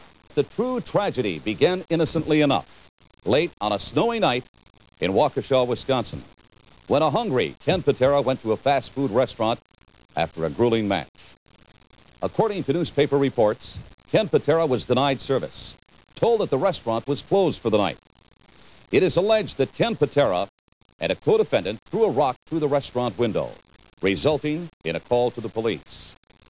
Then take it away, Mean Gene!
I love that recount, especially where Gene refers to, and I quote, “a hungry Ken Patera”, like he hadn’t eaten in three weeks.